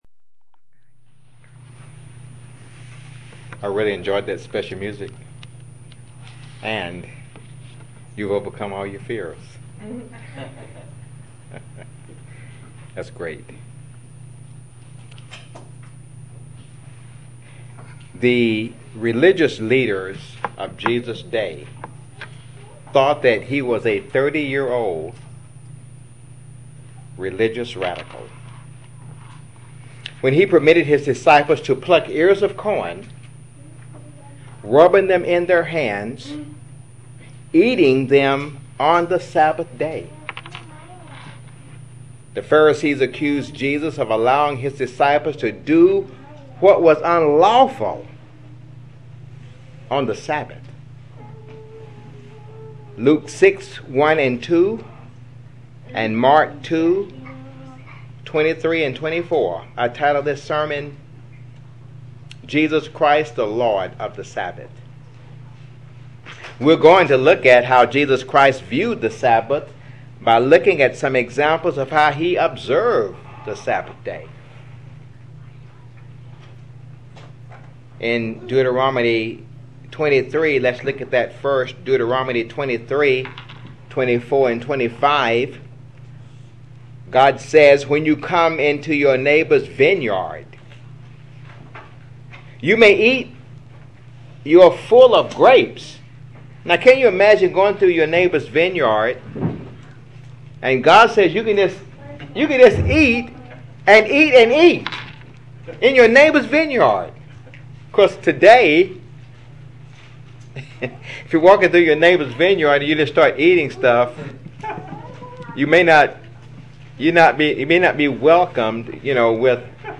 Mark:2:28 Luke:4:16-22 Luke:4:31-41 UCG Sermon Studying the bible?